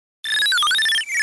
TechyBeep.ogg